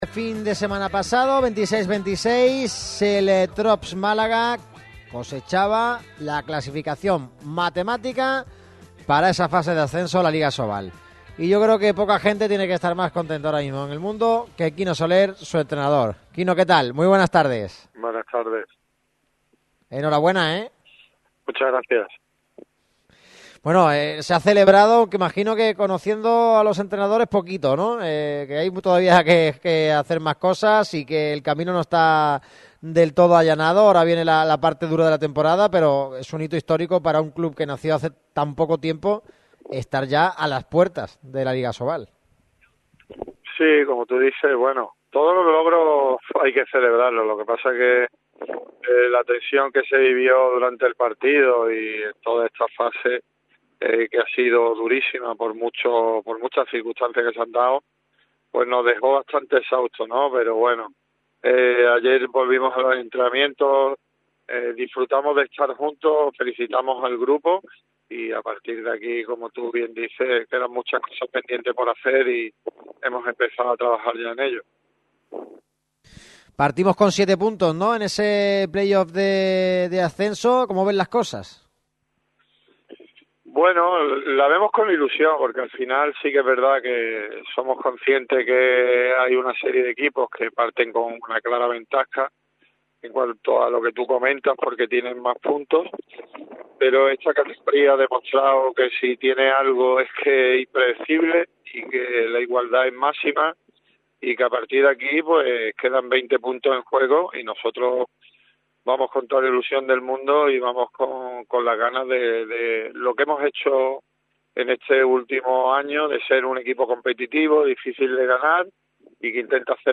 pasó por los micrófonos de Radio MARCA Málaga